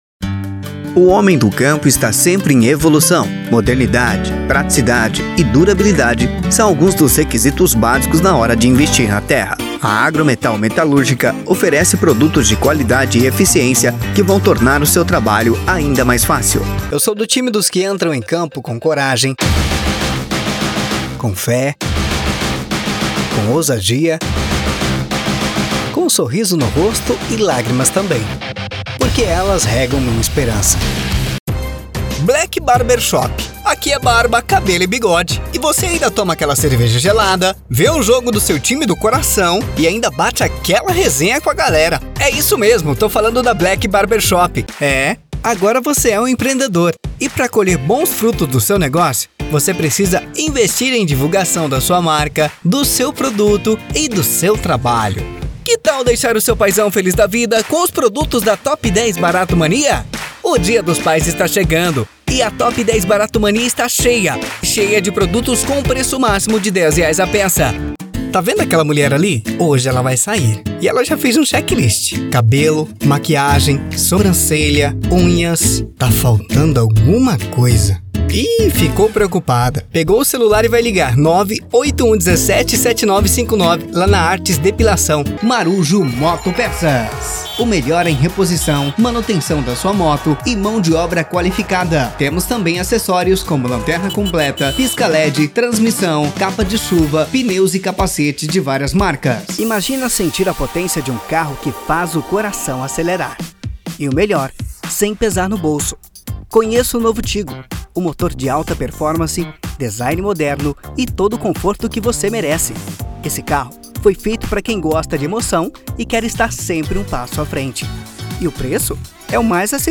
Spot Comercial
Padrão
Impacto
Animada
qualidade impecavel, estilo publicitario top